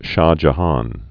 (shä jə-hän) 1592-1666.